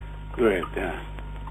Располагаю архивной записью с голосом дорогого Леонида Ильича.